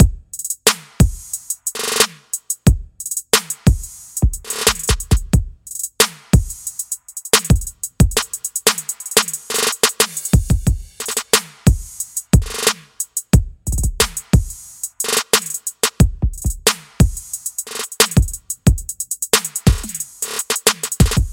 描述：调：Emin 速度：90bpm 一些俱乐部类型的Trap/hiphop循环。